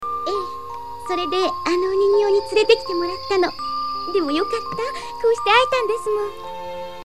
Audio file in Japanese. Ran, cute version, performed by You Inoue